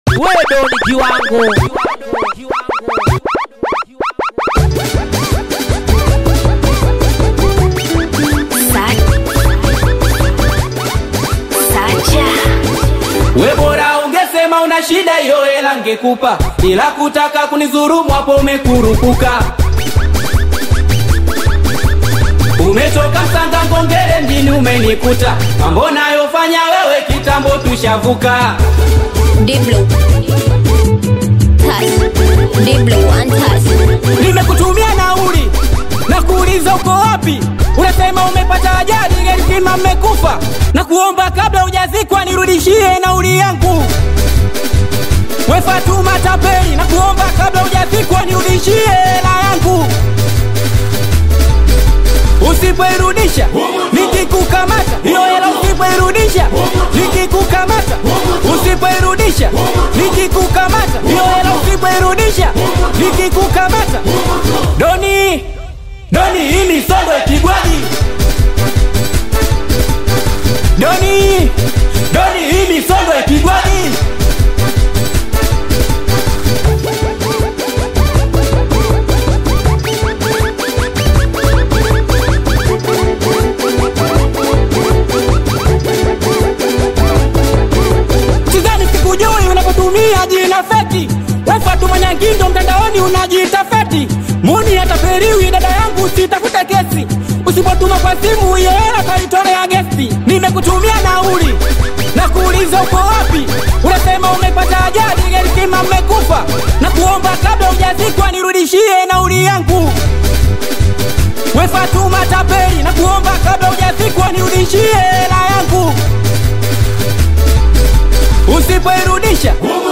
Singeli
Bongo Flava